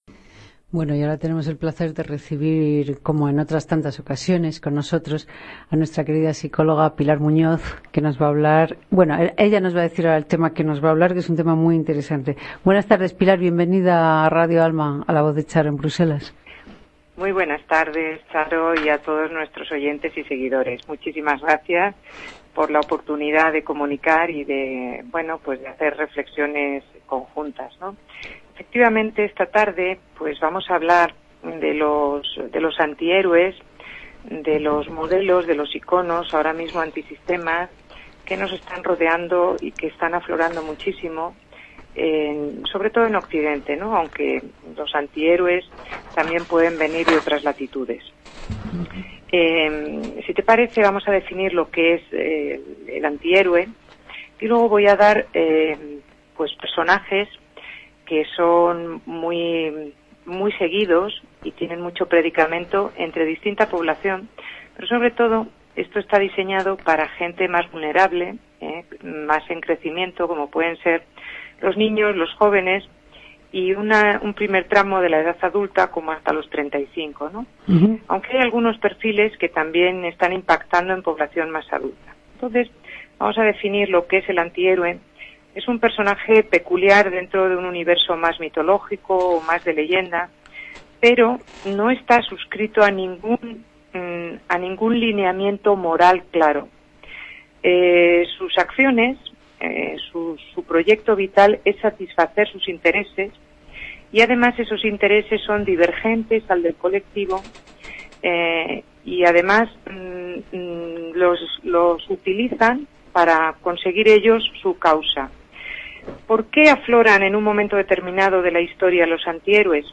Nuestra amable psicóloga